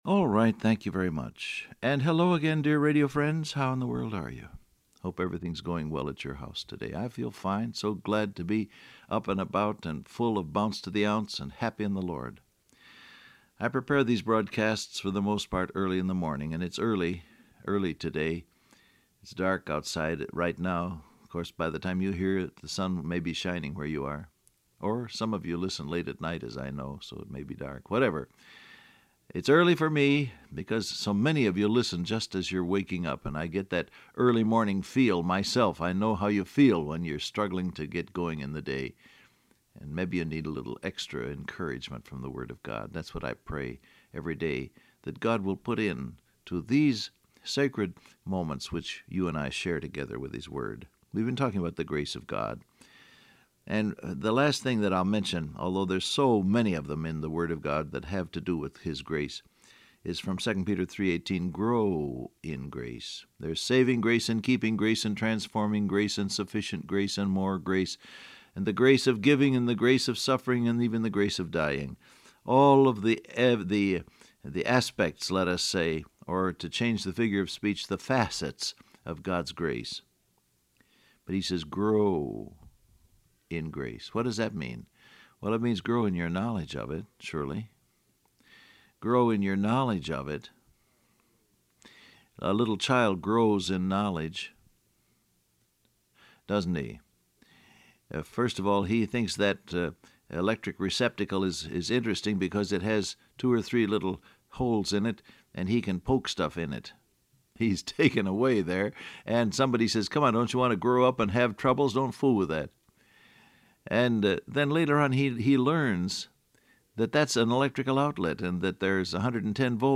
Download Audio Print Broadcast #6299 Scripture: Colossians 4:18 , 2 Peter 3:18 Topics: Grace , Growth , Tests Transcript Facebook Twitter WhatsApp Alright, thank you very much, and hello again dear radio friends.